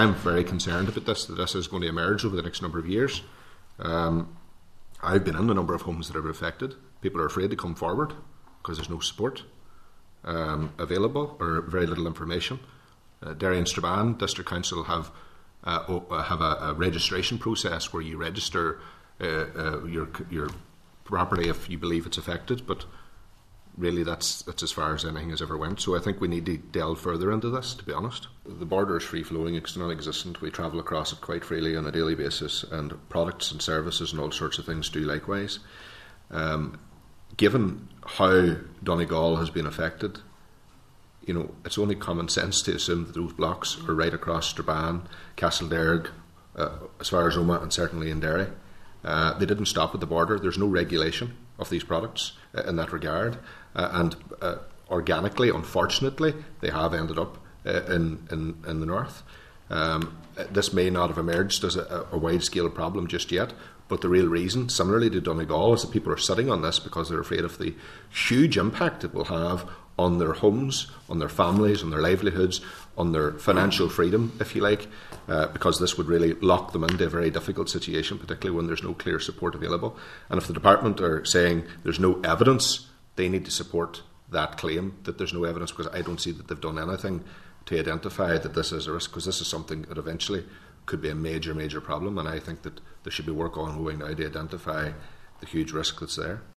He told a Committee meeting that people are living in fear as no support is available: